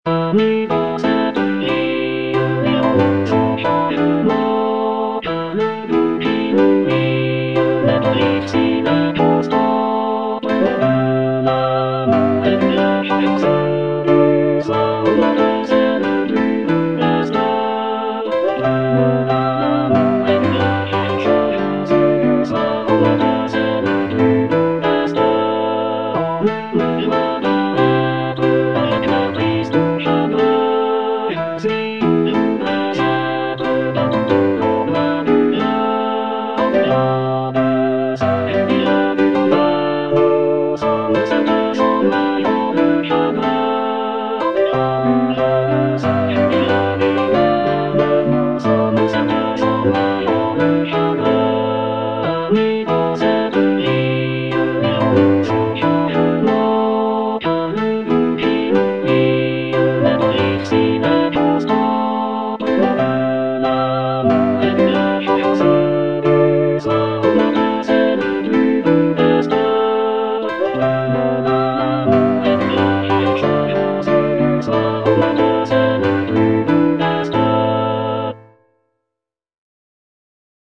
All voices
traditional French folk song